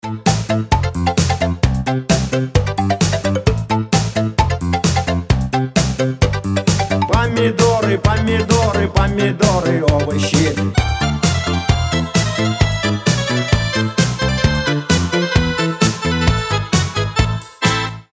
Панк-рок , Частушки